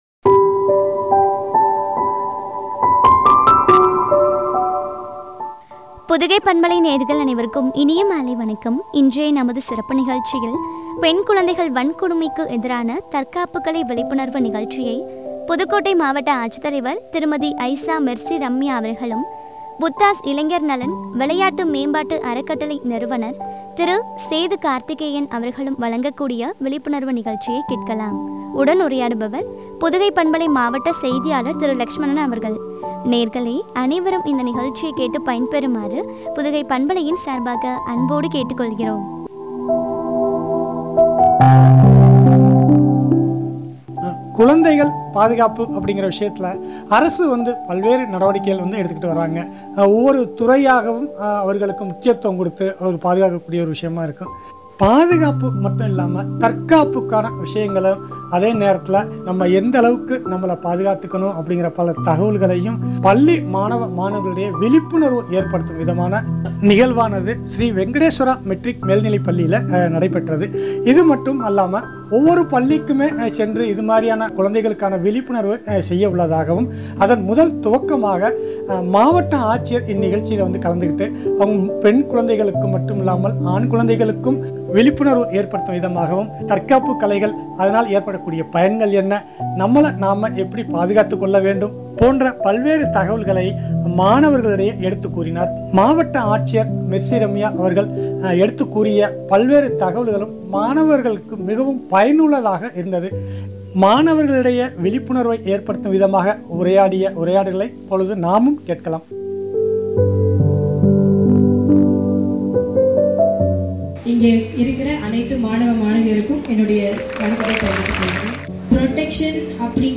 பெண் குழந்தைகள் வன்கொடுமைக்கு எதிரான தற்காப்பு கலை விழிப்புணர்வு குறித்து வழங்கிய உரையாடல்.